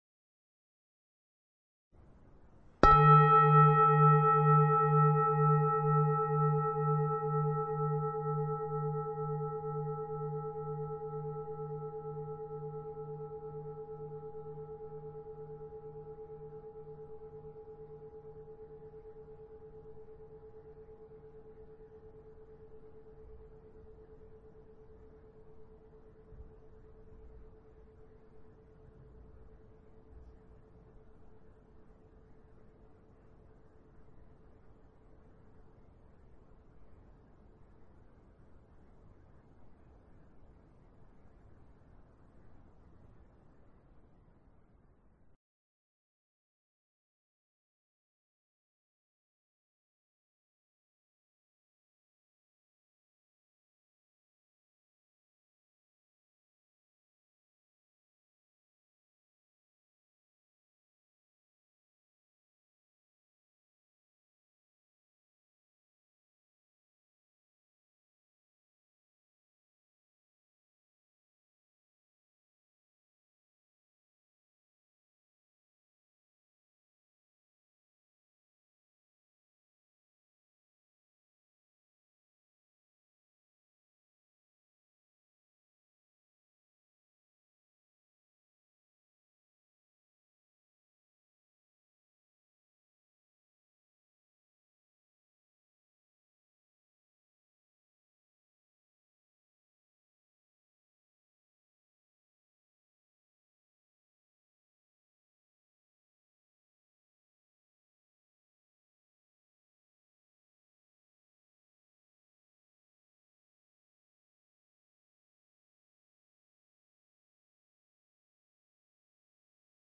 Meditation-20min.mp3